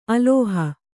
♪ alōha